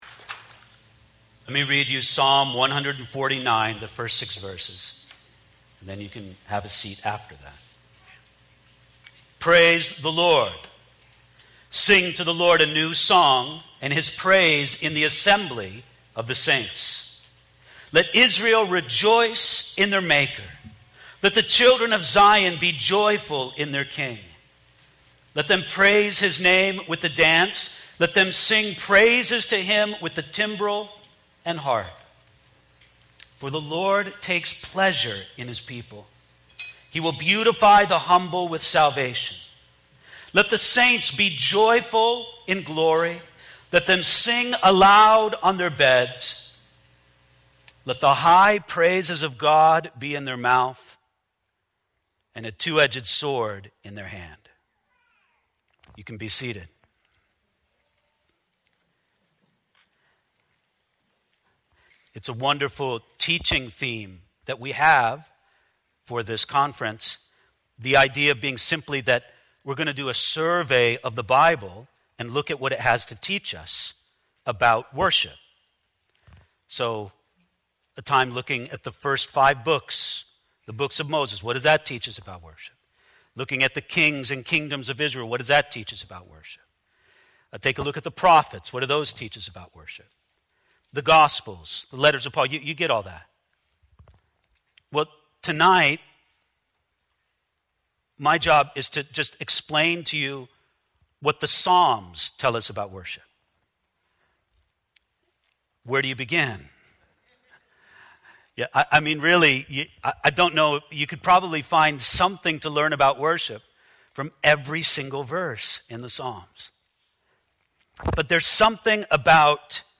Home » Sermons » DSWC 2023 – Devotional